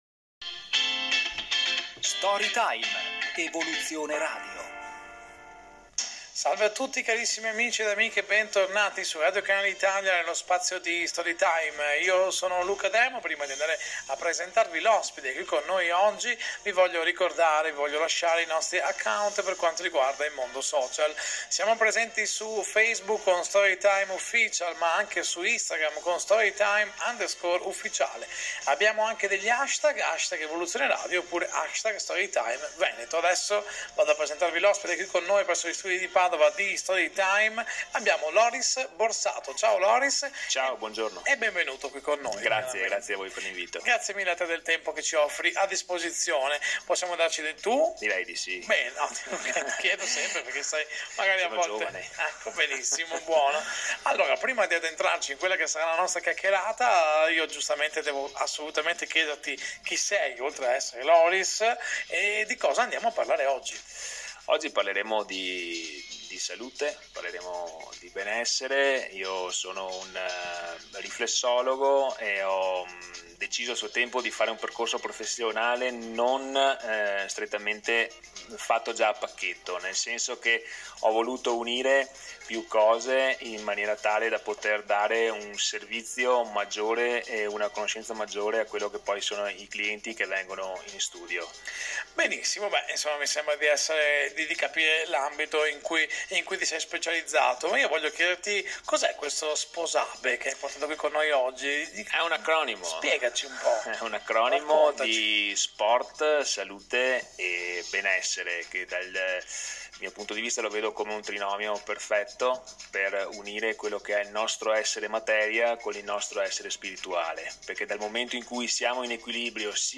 VIDEO INTERVISTE